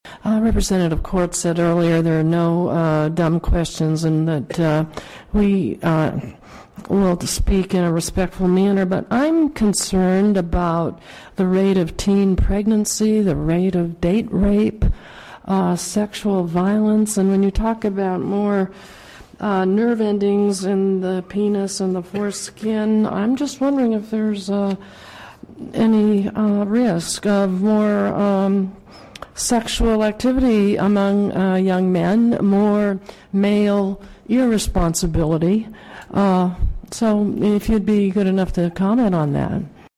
Rep. Schafer asked (audio, excerpted from the legislature’s archive):